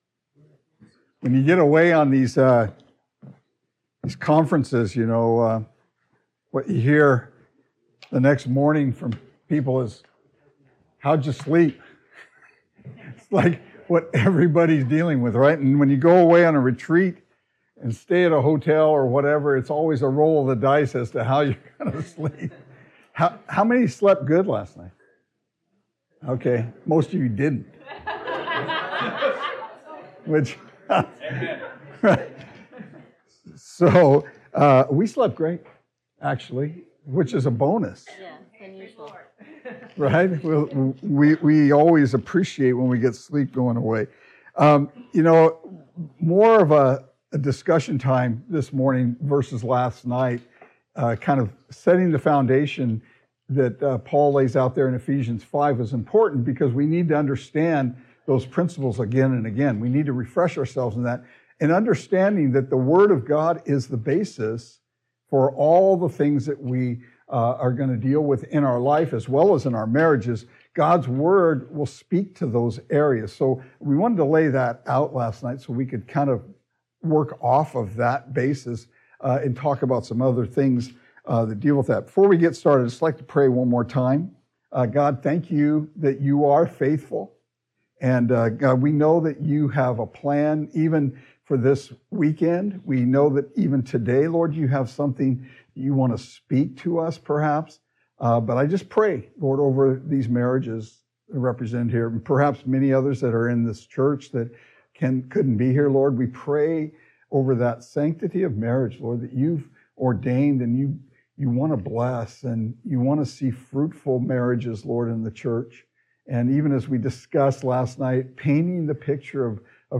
Marriage Retreat 2025 Current Sermon